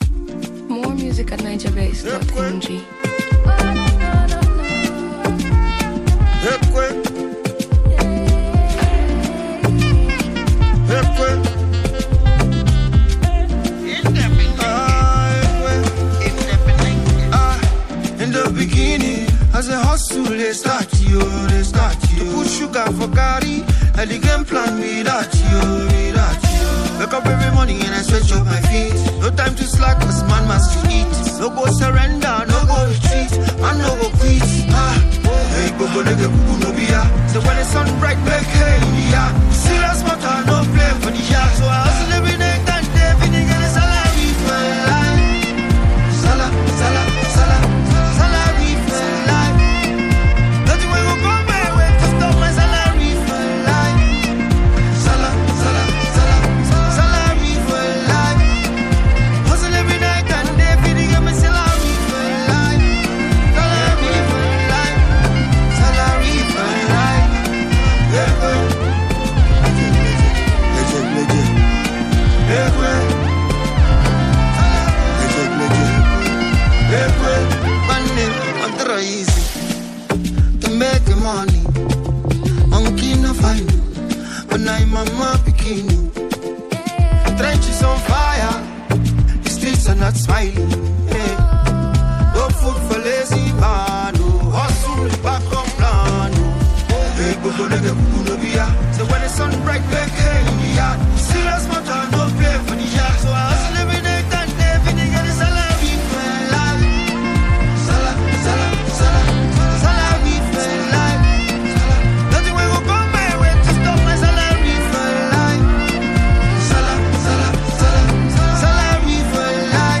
smooth, inspiring Afropop tune